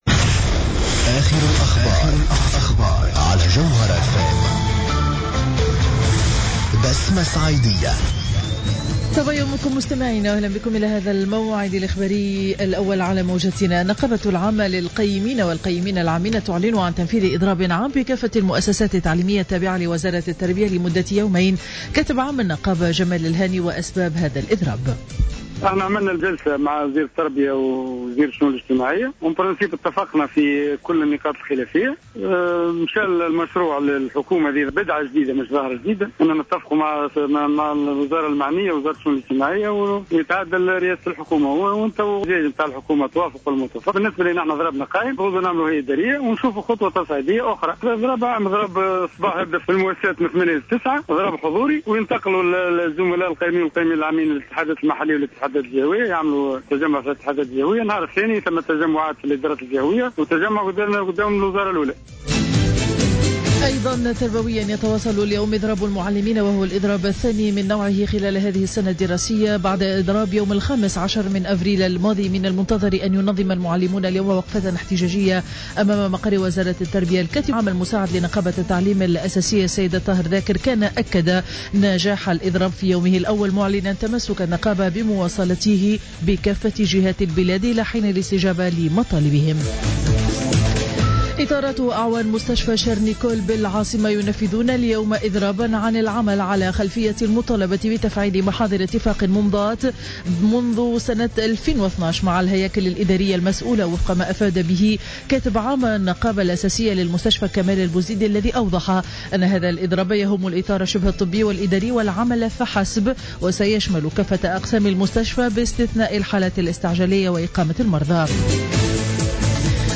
نشرة أخبار السابعة صباحا ليوم الأربعاء 13 ماي 2015